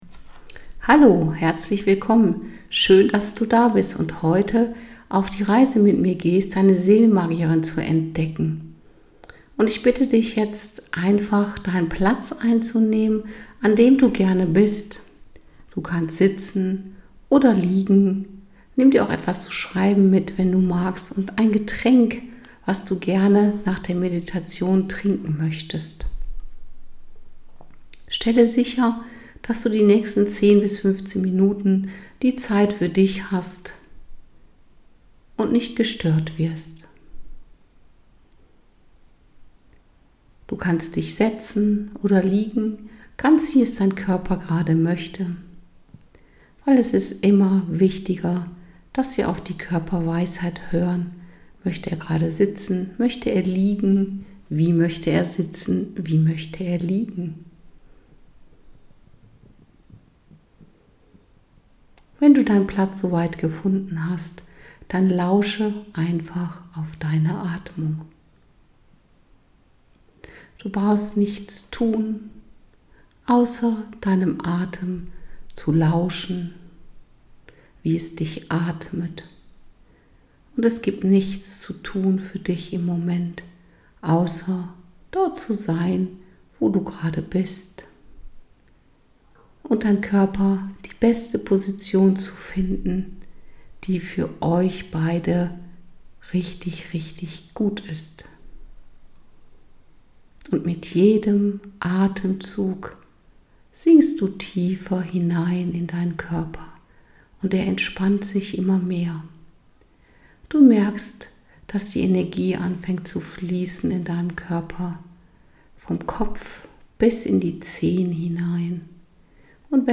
seelenmagierin-meditation.mp3